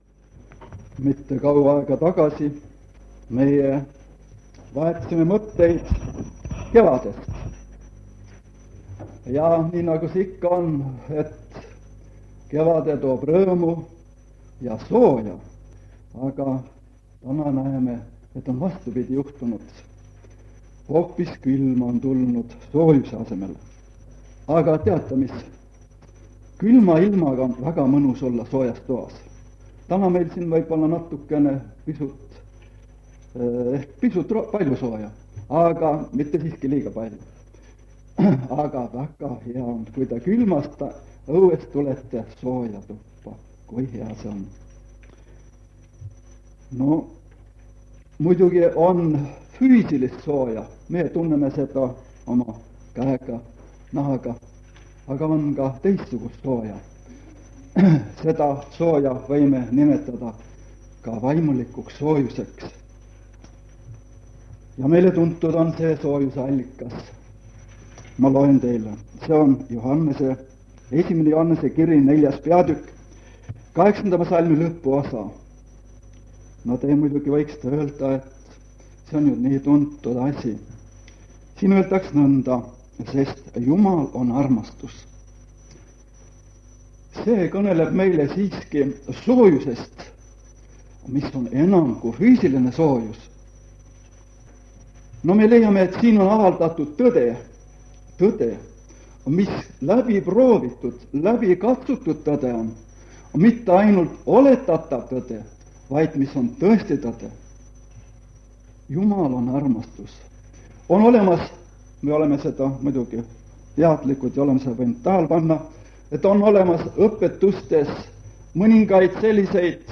Tema kõne teemaks on E L U K O O L Lisaks kuuleme nelja lauluettekannet ja kahe koguduse venna lühemaid kõnesid.
Koosolekute helisalvestused
Jutlusi vanalt lintmaki lindilt.